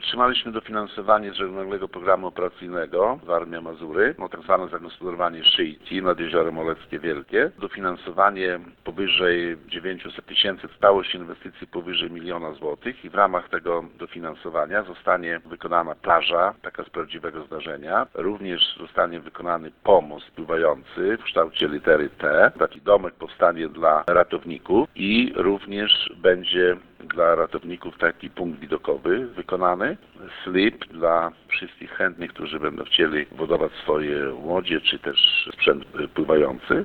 – Będzie bezpiecznie i funkcjonalnie – mówi Wacław Olszewski, burmistrz Olecka.